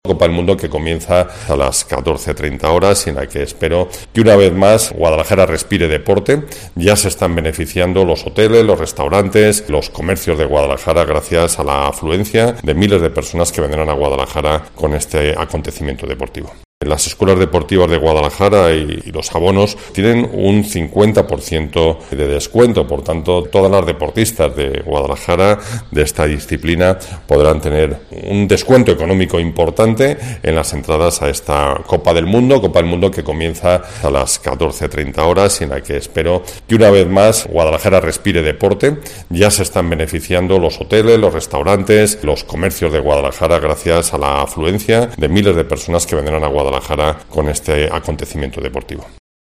Antonio Román, Alcalde de Guadalajara